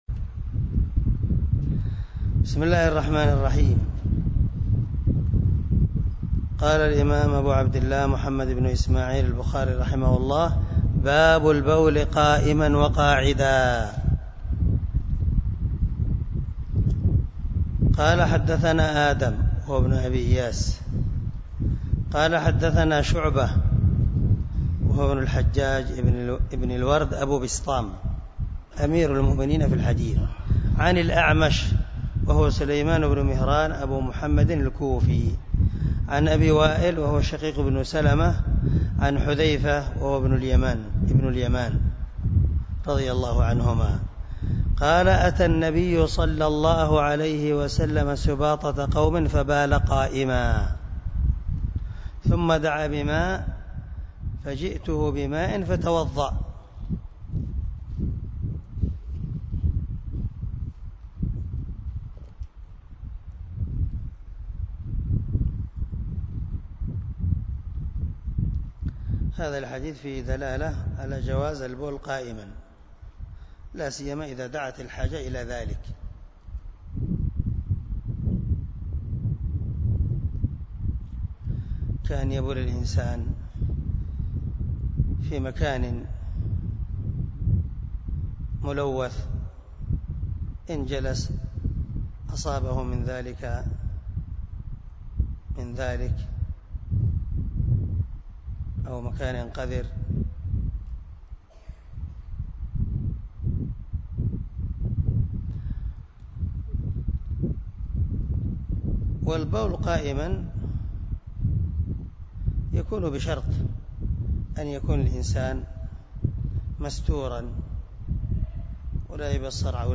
190الدرس 66 من شرح كتاب الوضوء حديث رقم ( 224 – 226 ) من صحيح البخاري